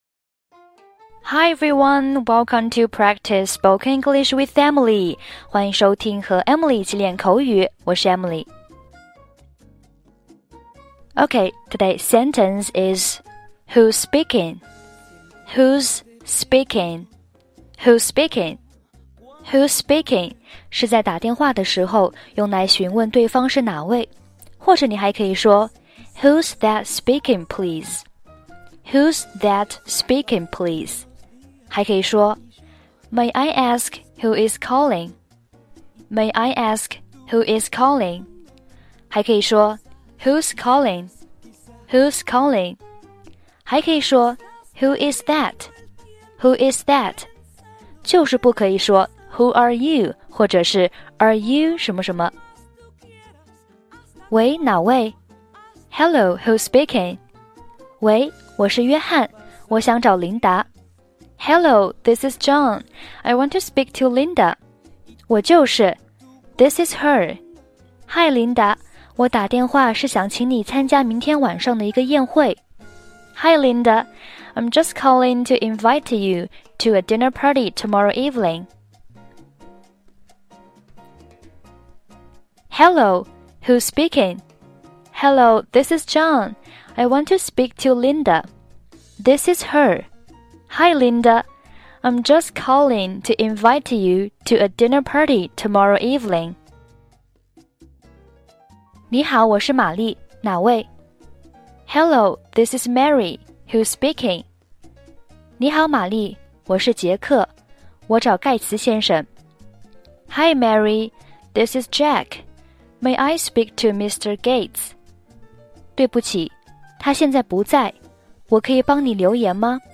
背景音乐：